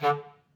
Clarinet
DCClar_stac_D2_v3_rr1_sum.wav